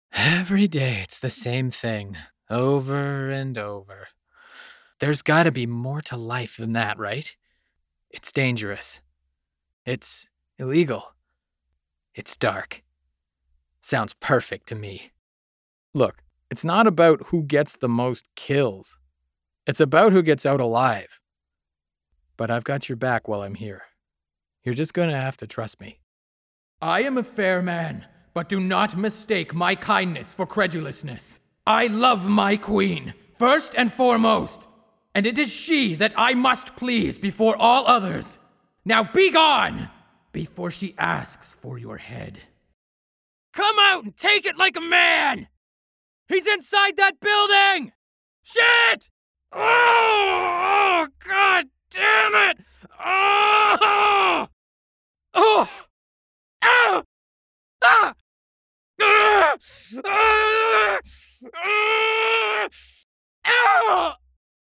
Male
Bright, Confident, Corporate, Friendly, Natural, Warm, Approachable, Assured, Character, Cheeky, Conversational, Cool, Energetic, Engaging, Funny, Reassuring, Sarcastic, Soft, Upbeat, Versatile, Wacky, Witty, Young
Canadian English (native) American English
explainer.mp3
Microphone: Rode NT1-A
Audio equipment: Rode NT1-A mic, Focusrite Scarlett 2i2 preamp, Soundproof, Rockwool acoustic panel treated recording space